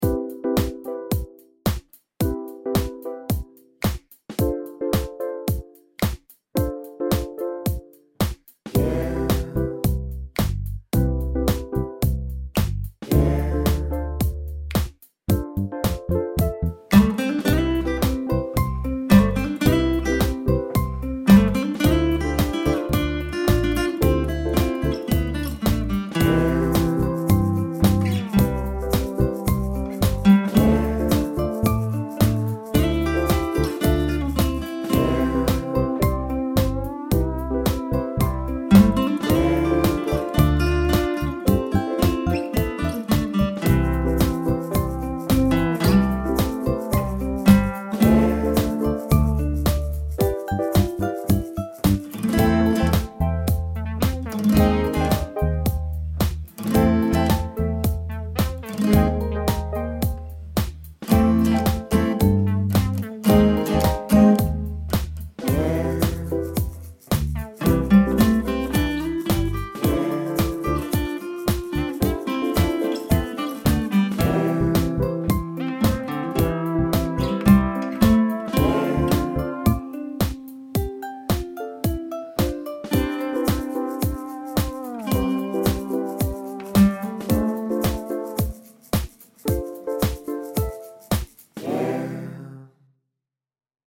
grabando con el ipad y el garageband
Aquí dejo una prueba de lo que se puede hacer con el iPad y con el programa GarageBand (cuesta 4 euros en la App store).